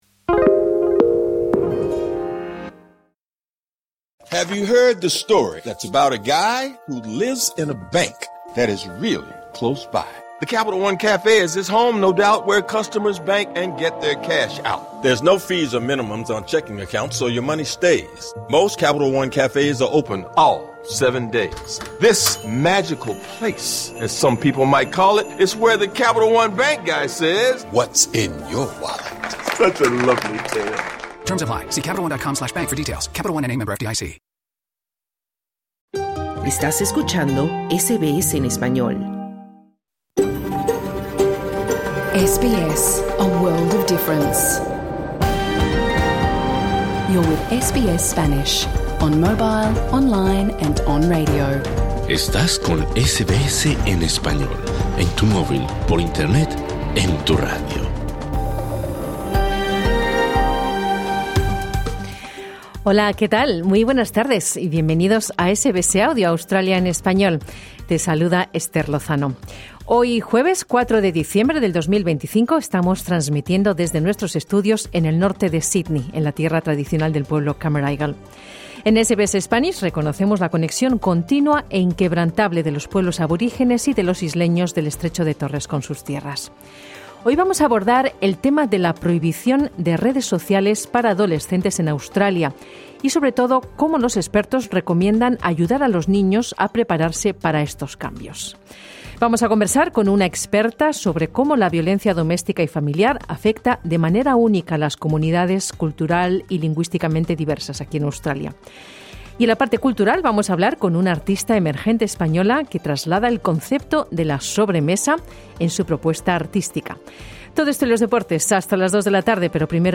TEMAS DEL PROGRAMA: Hablamos de la prohibición de las redes sociales para adolescentes en Australia y cómo los expertos recomiendan ayudar a los niños a prepararse para esos cambios. Conversamos con una especialista sobre cómo la violencia doméstica y familiar afecta de manera única a las comunidades cultural y lingüísticamente diversas en Australia. Charlamos con una artista emergente española que traslada el concepto de la sobremesa a su pintura.